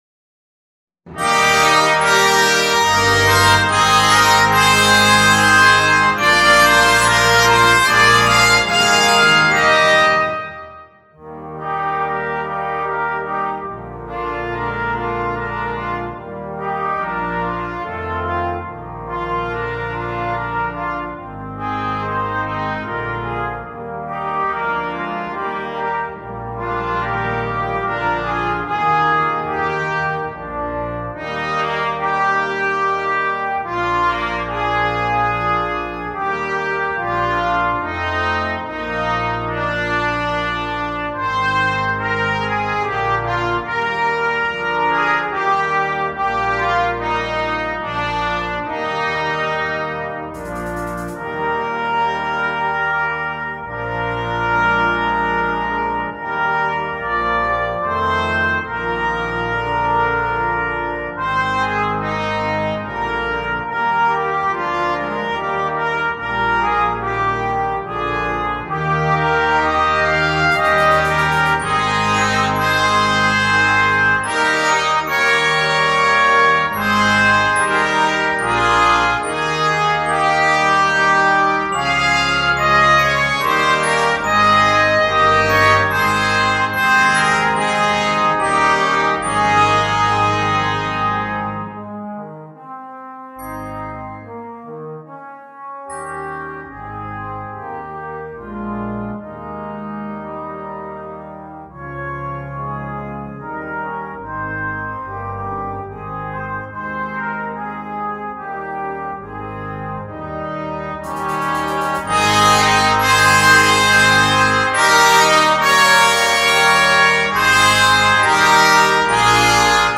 2. Jeugdorkest (flex)
8 Delen & Slagwerk
zonder solo-instrument
Kerstmuziek
Part 1 in C (8va): Flute
Percussion